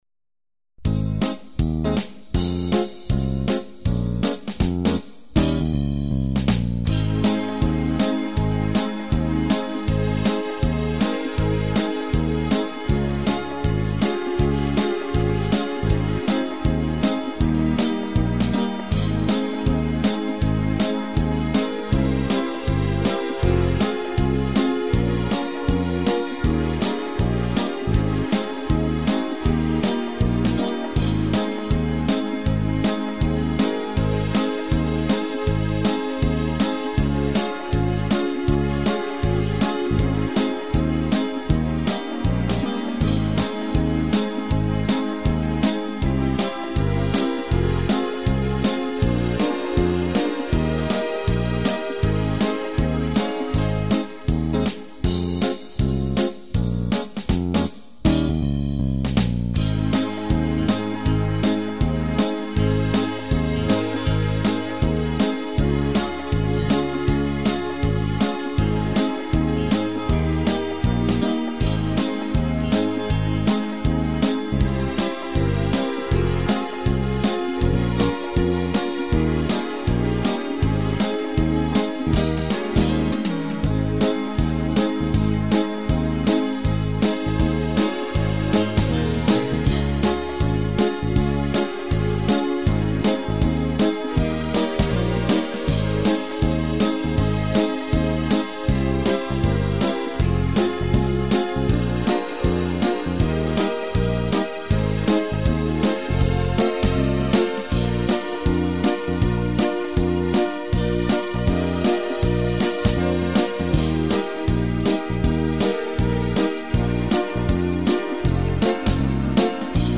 Kategorie: Playbacks-KARAOKE
Old american spiritual song